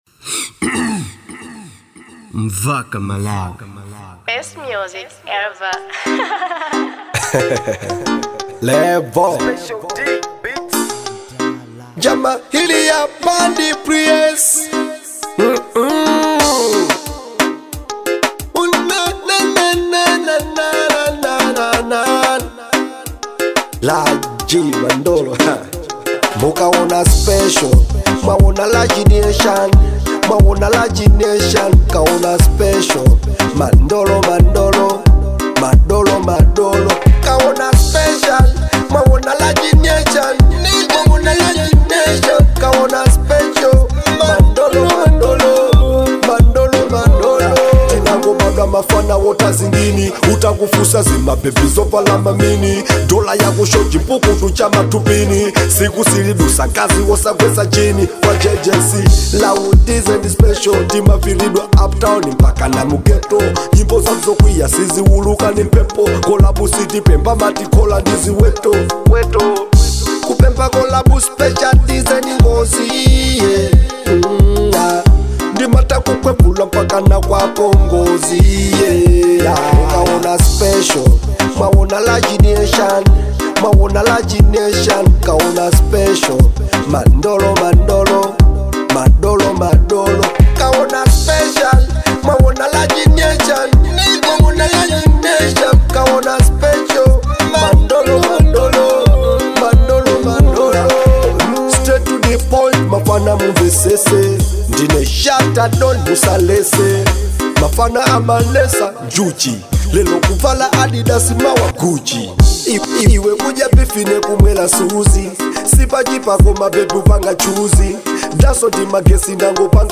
type:Dancehall